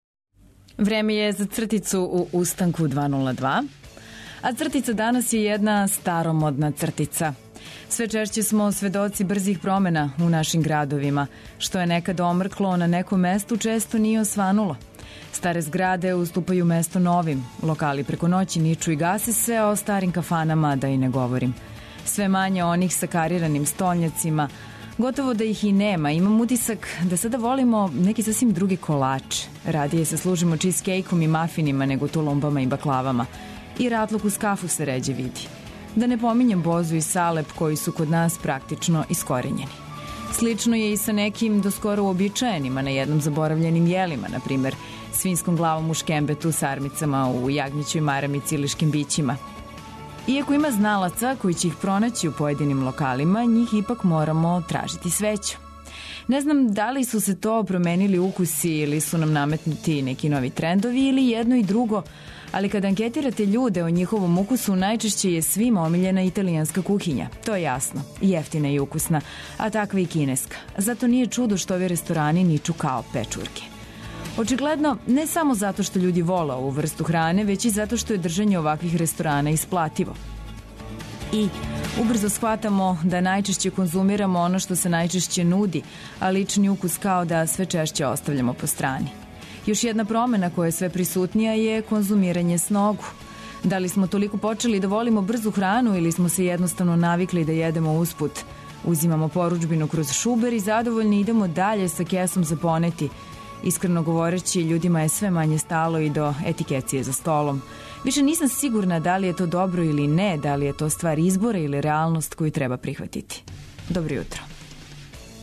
Уобичајено нудимо смех, музику за разбуђивање, нове вести, сервисне информације, низ радијских прилога и, наравно, добро расположење!